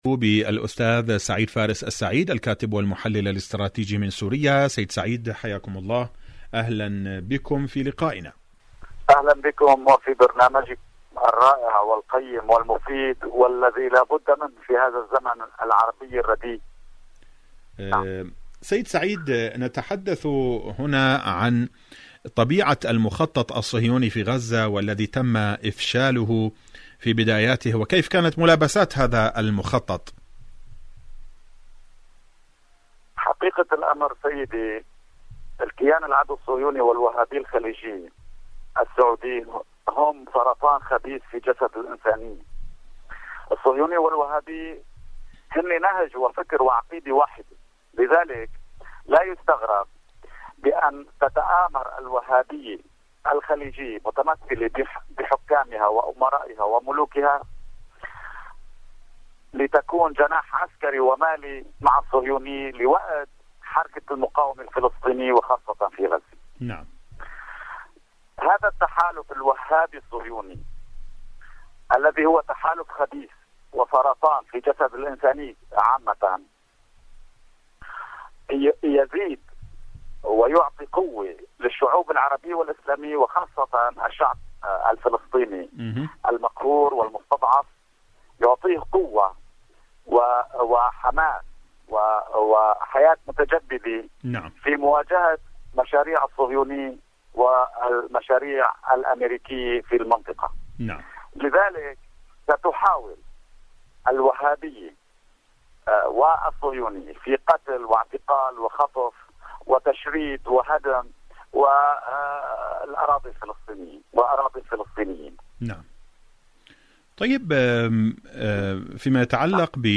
مقابلة إذاعية
البث المباشر